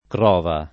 Crova [ kr 0 va ]